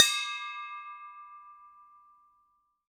bell_small_ringing_03.wav